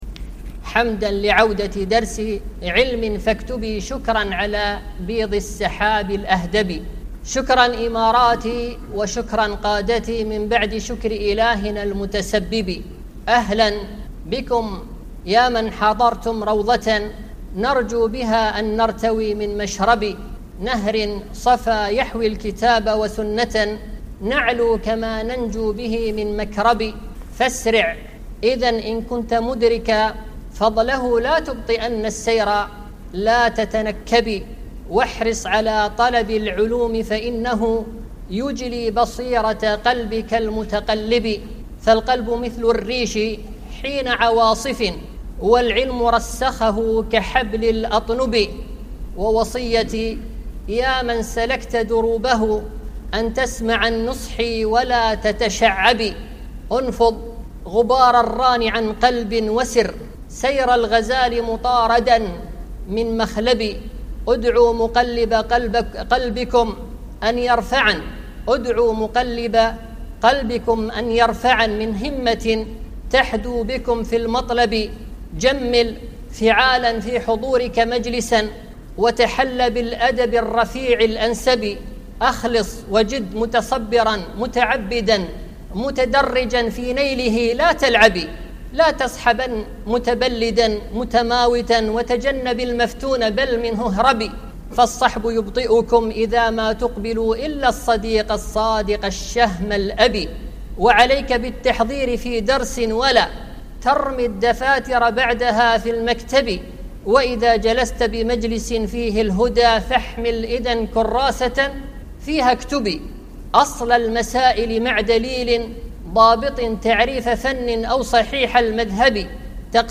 أبيات شعرية (حمد وشكر على عودة الدروس مع جملة من آداب طالب العلم)